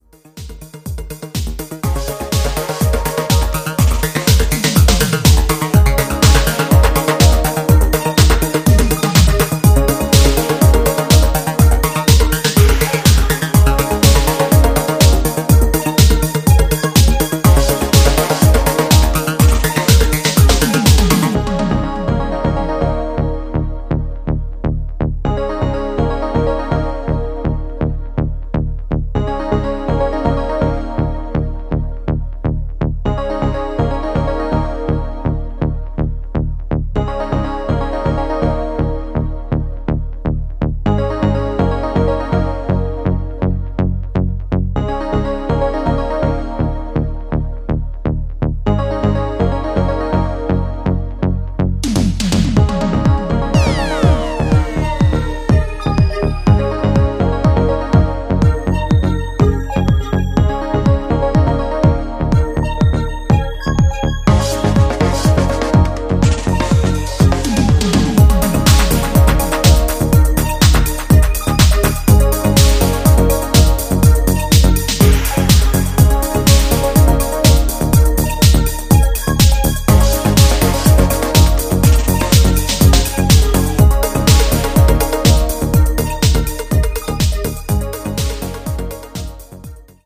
グッと落としたテンポとシンセベースのリフ、カラフルなメロディーが心地良い